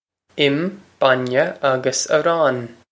Pronunciation for how to say
im, bon-yeh ogg-us a-rawn.
This is an approximate phonetic pronunciation of the phrase.